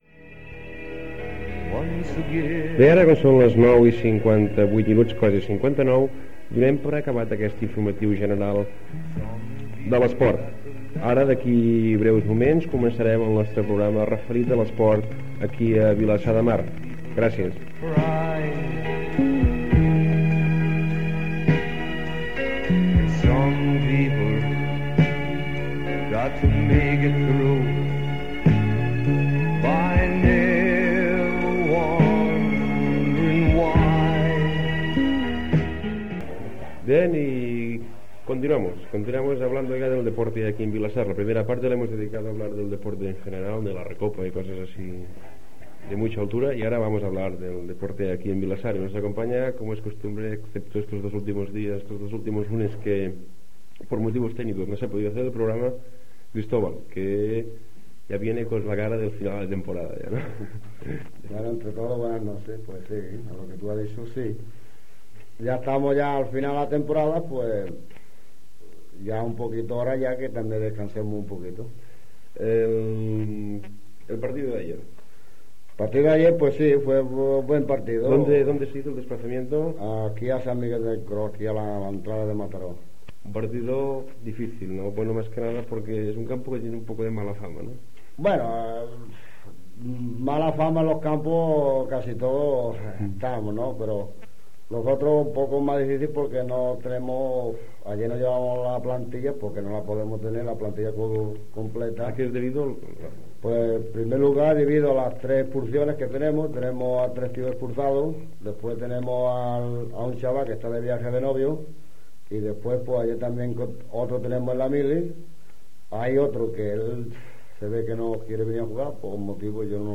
Programa esportiu: partit de futbol de l'Atlètic Vilassanès
Esportiu